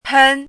chinese-voice - 汉字语音库
pen1.mp3